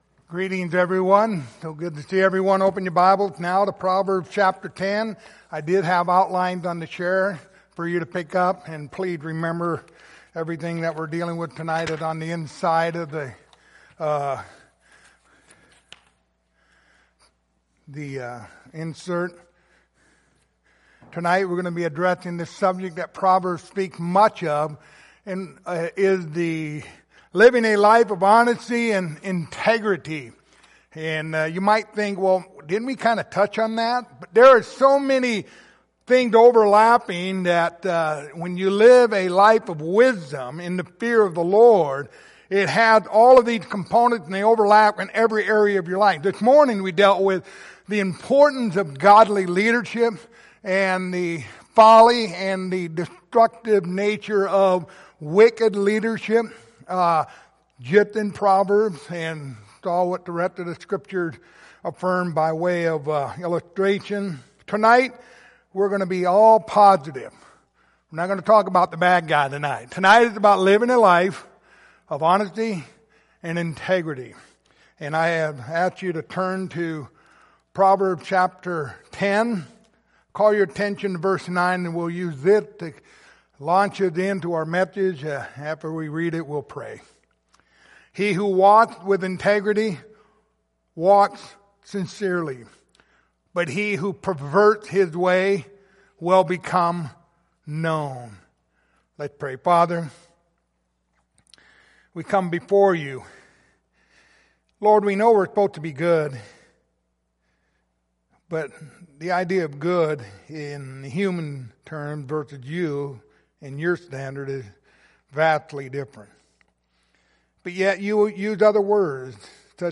Series: The Book of Proverbs Passage: Proverbs 10:9 Service Type: Sunday Evening